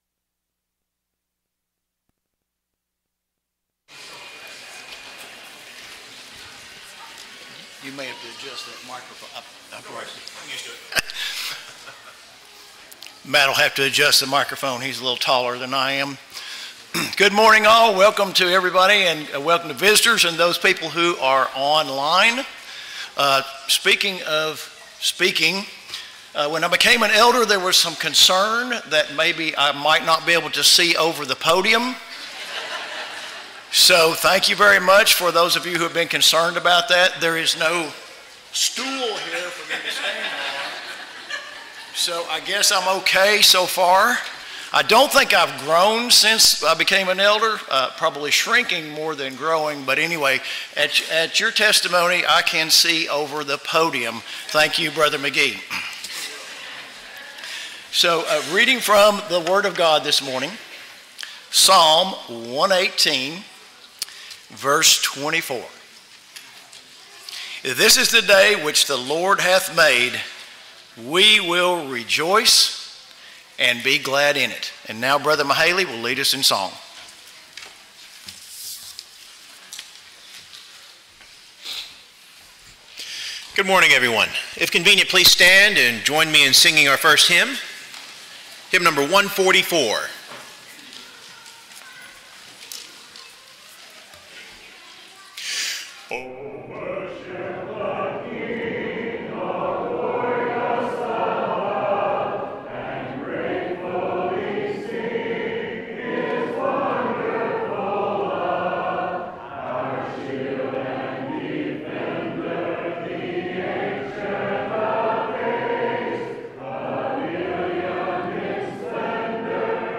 Ephesians 6:4, English Standard Version Series: Sunday AM Service